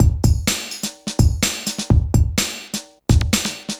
• 126 Bpm Drum Loop Sample E Key.wav
Free drum loop sample - kick tuned to the E note.
126-bpm-drum-loop-sample-e-key-z0r.wav